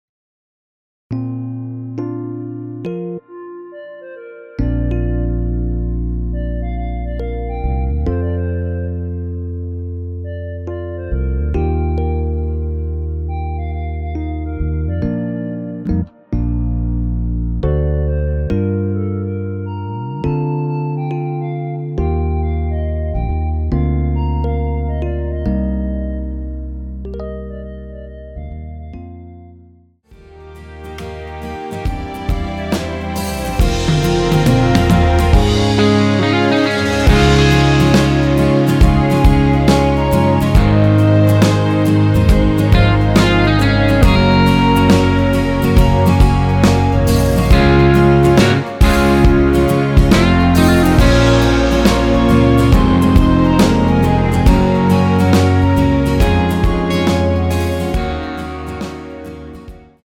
전주 없이 시작 하는 곡이라 전주 1마디 만들어 놓았습니다.(미리듣기 참조)
원키 멜로디 포함된 MR입니다.
Bb
앞부분30초, 뒷부분30초씩 편집해서 올려 드리고 있습니다.